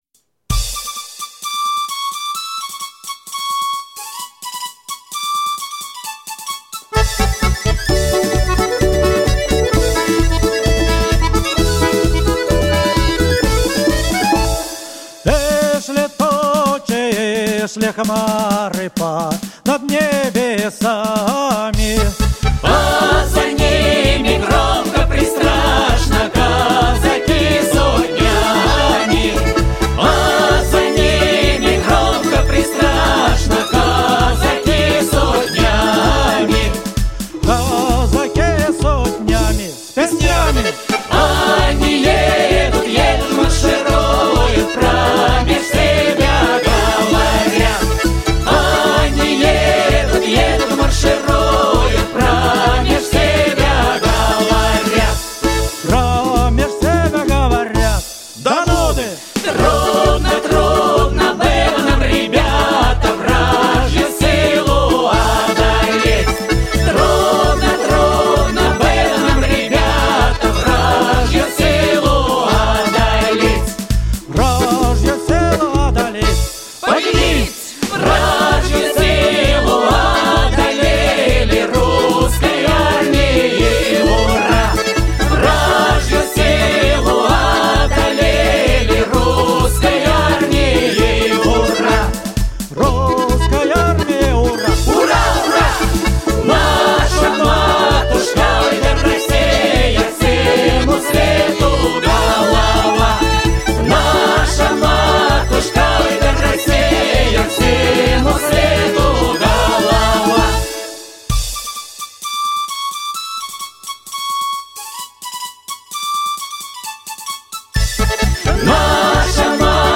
Коллектив почти год трудился в студии звукозаписи